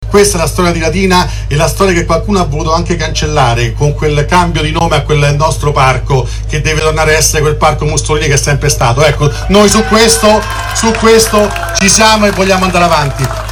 Sentiamo cosa ha detto nei giorni scorsi in un comizio a Latina: